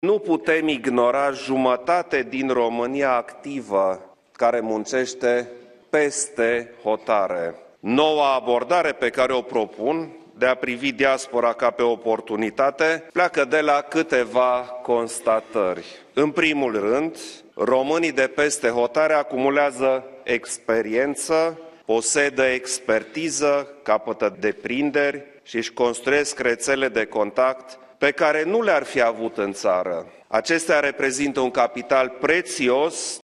Astăzi, la Palatul Cotroceni, președintele a atras atenția că diaspora reprezintă un capital uriaș pentru cei de acasă.